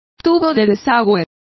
Complete with pronunciation of the translation of drainpipes.